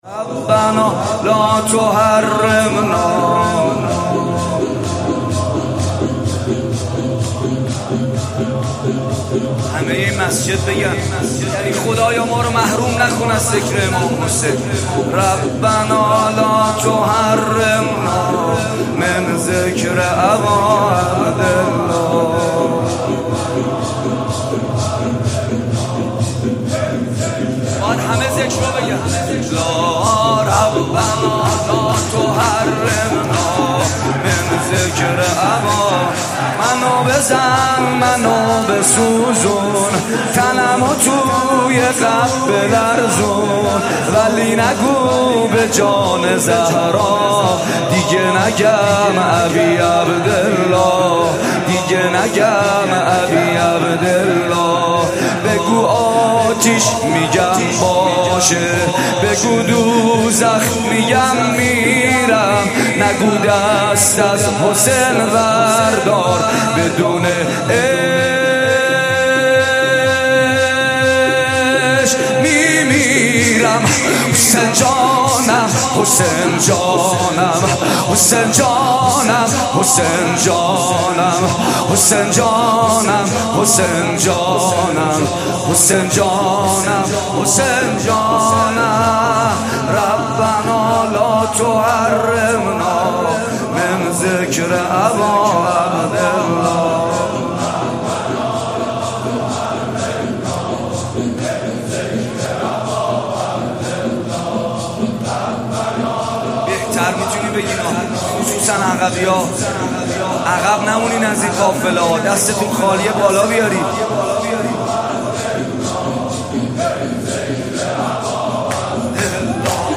در شب پنجم محرم در مراسمی که در حسینیه آیت الله حق‌ شناس (ره) برگزار شد به مرثیه‌ خوانی و روضه ‌خوانی پرداخت.
روضه خوانی مرثیه خوانی